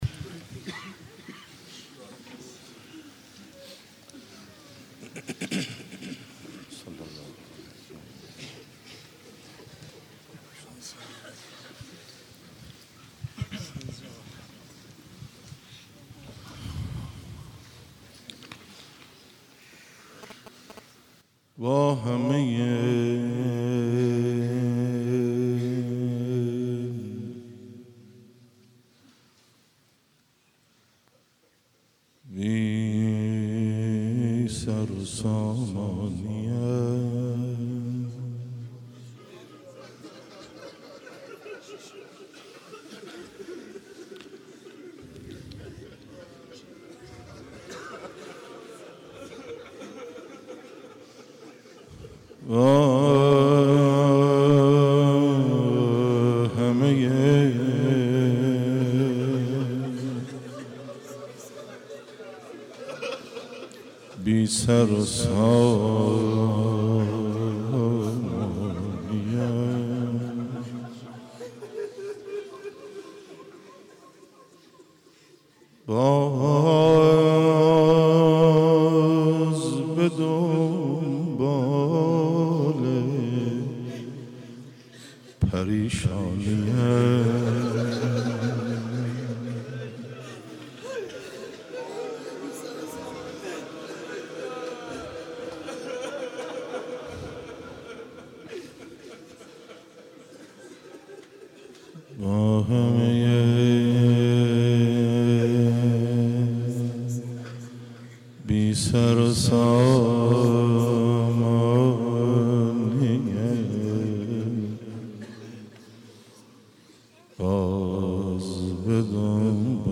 فاطمیه 96 - روضه - با همه بی سر و سامانی ام